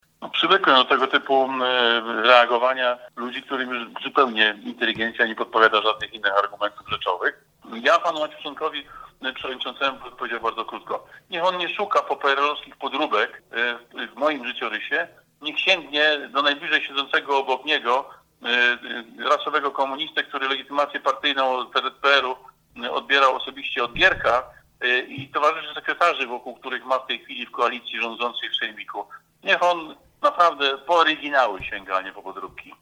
Do gorących zajść doszło podczas dzisiejszej sesji sejmiku lubuskiego.
– Niech radny Maciuszonek poszuka oryginalnych komunistów wokół siebie – tak skwitował wypowiedź przewodniczącego sejmiku Marek Surmacz: